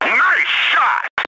hit4.ogg